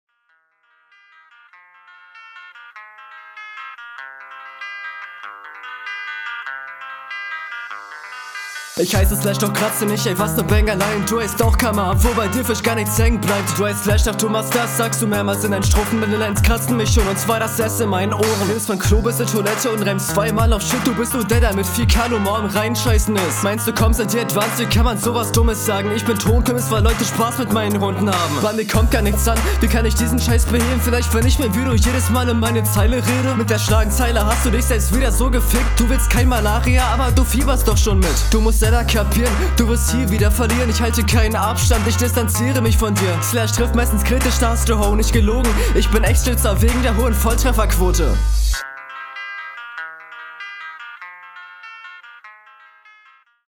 Stimmeinsatz weniger druckvoll, aber das ruhige passt auch. Flowlich sehr solide, doch kaum Variation.
Auch ne Coole Stimme und n cooler Einstieg, nice! Ziemlich lässiger Flow, sehr schön!